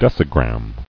[dec·i·gram]